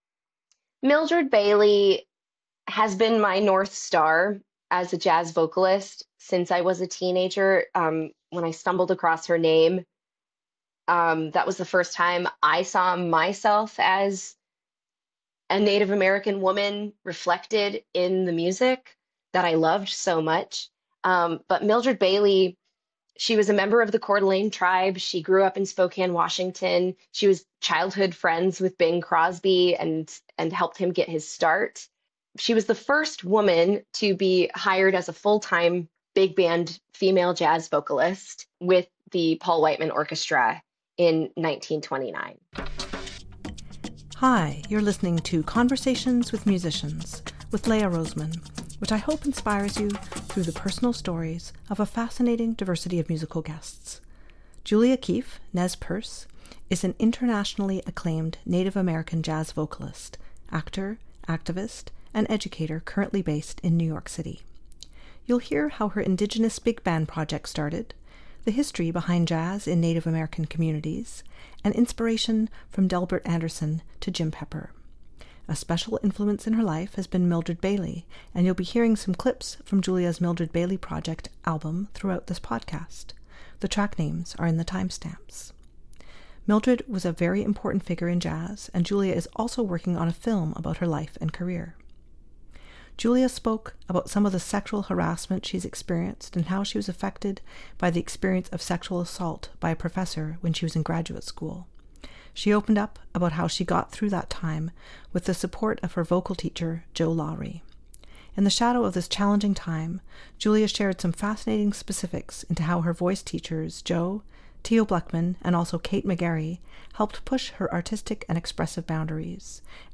I’m so happy to share this meaningful conversation with you.